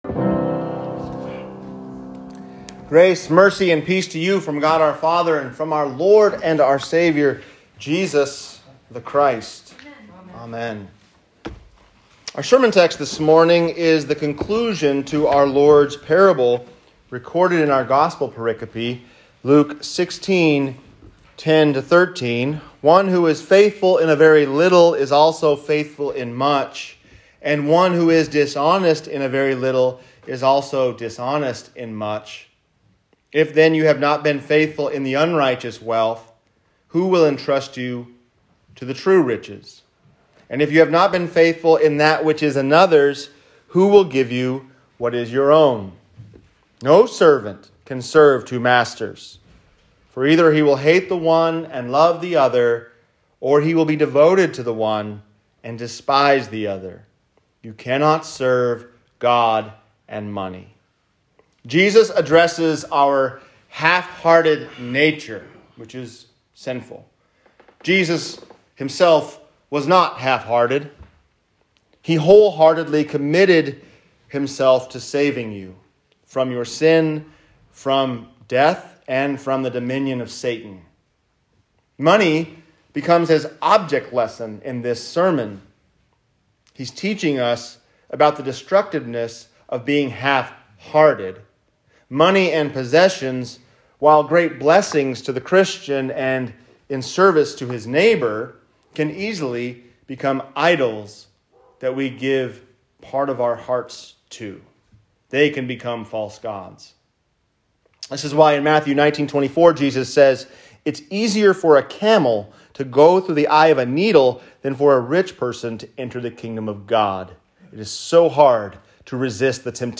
On Being Wholehearted | Sermon